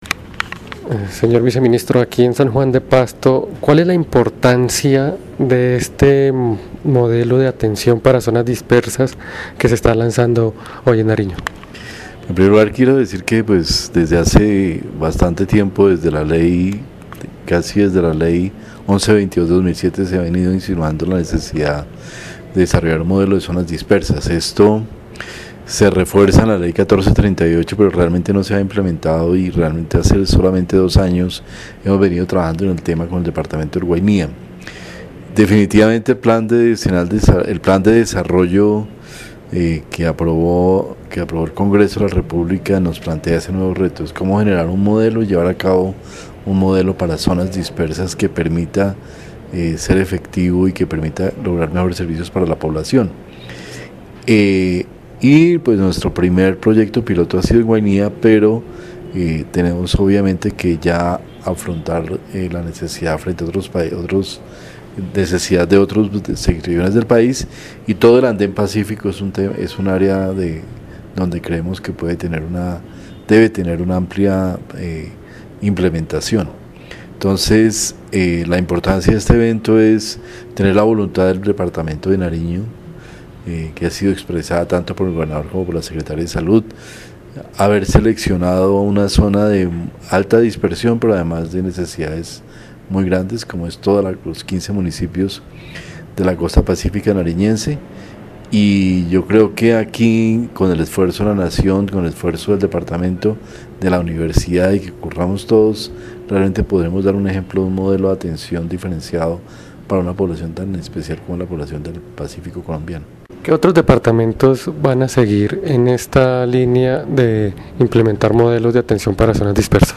Viceministro de Salud Pública y Prestación de Servicios, Fernando Ruíz Gómez
Audio: declaraciones de ViceSalud sobre lanzamiento modelo de zonas dispersas en Nariño